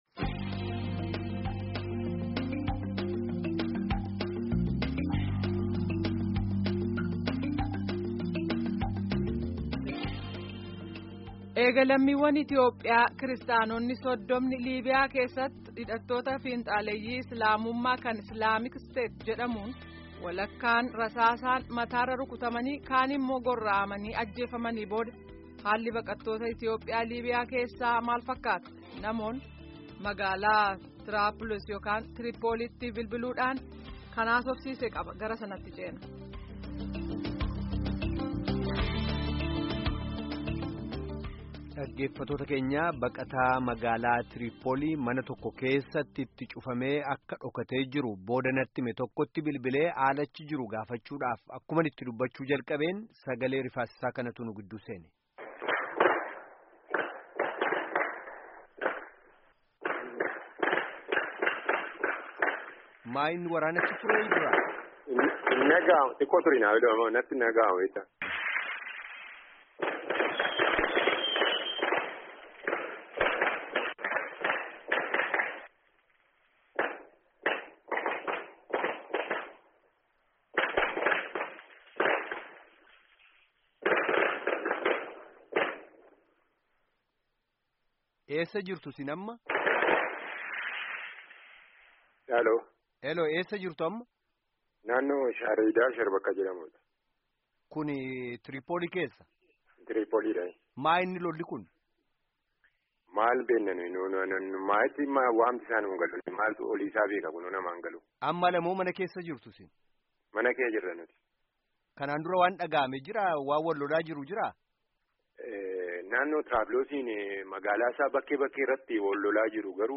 Baqataa Trippoolii Keessaa Sagalee Dhukaasaa Gidduu Dubbatu